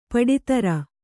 ♪ paḍi tara